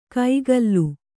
♪ kaigallu